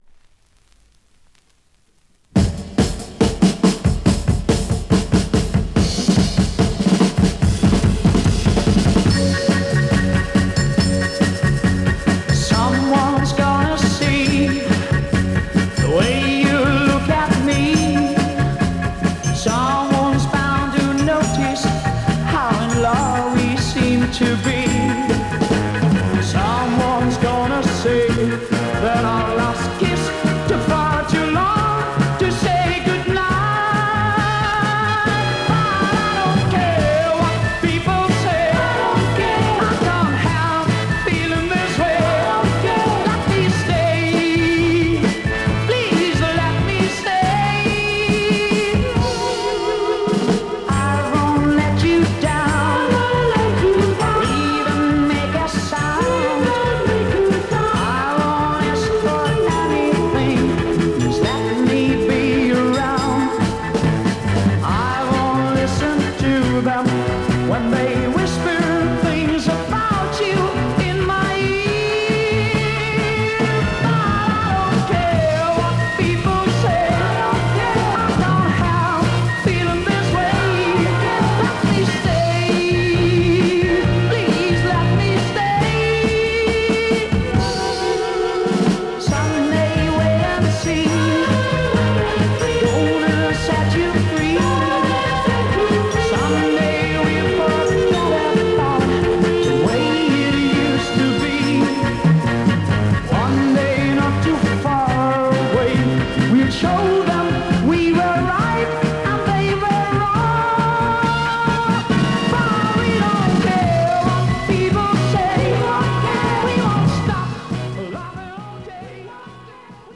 Classic Spanish Freakbeat French promo 7"
Classic Spanish Freakbeat soul mod Rare French promo 7"